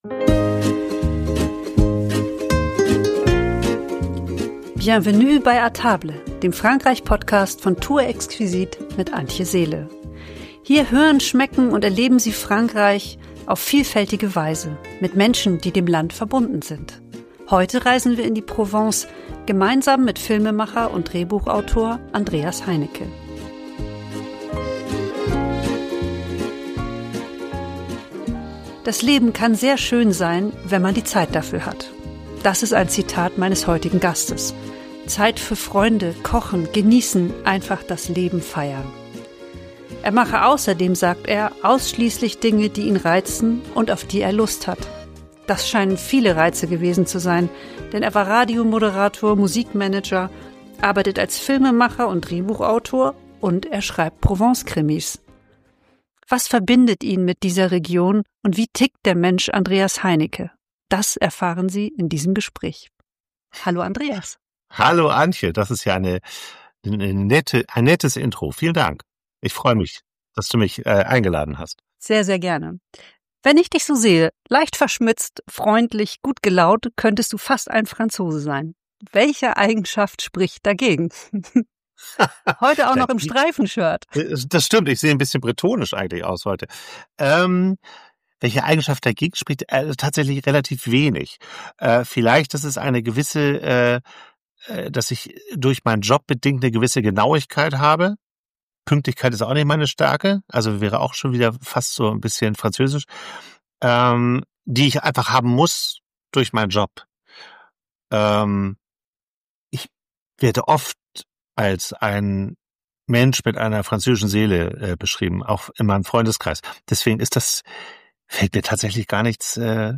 Ein Gespräch über Genuss, Schreiben und die Kunst, sich Zeit zu nehmen.